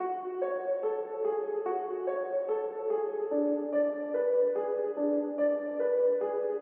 黑暗陷阱钢琴 145BPM
Tag: 145 bpm Trap Loops Piano Loops 1.11 MB wav Key : F FL Studio